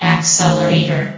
S.P.L.U.R.T-Station-13/sound/vox_fem/accelerator.ogg
* New & Fixed AI VOX Sound Files
accelerator.ogg